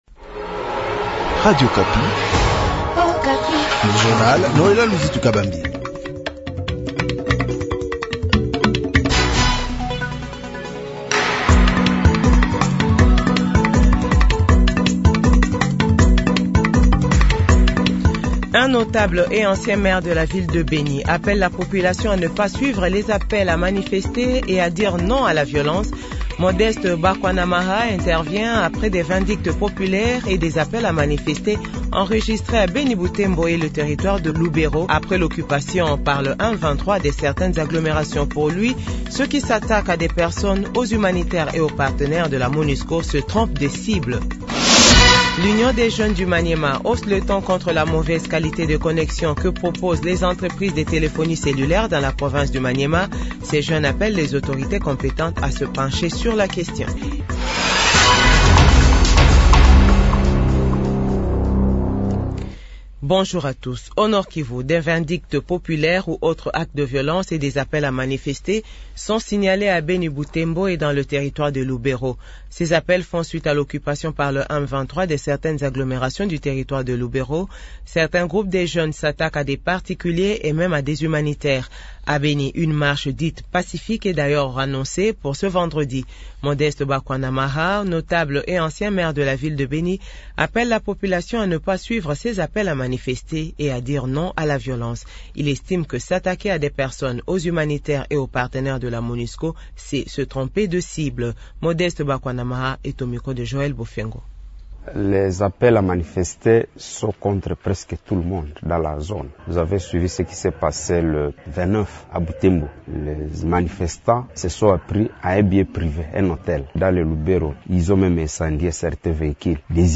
JOURNAL FRANCAIS 08H00